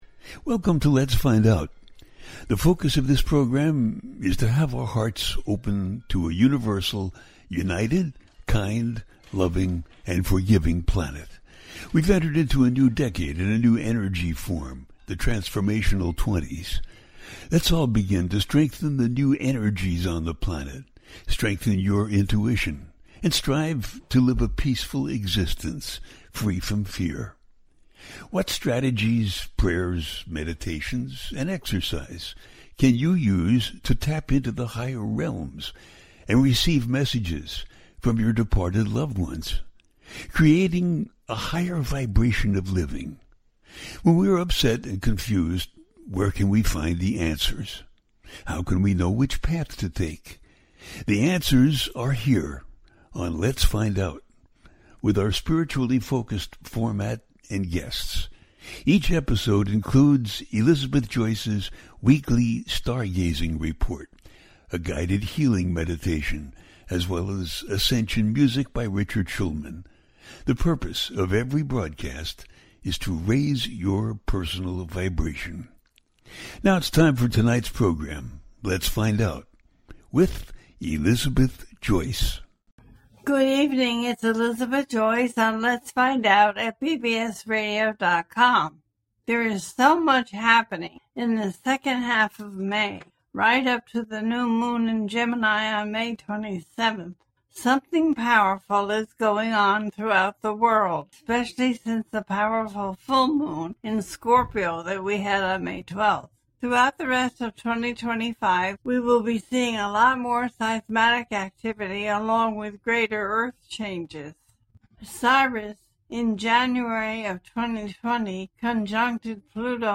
2nd Half Of May And The Gemini New Moon - A teaching show
The listener can call in to ask a question on the air.
Each show ends with a guided meditation.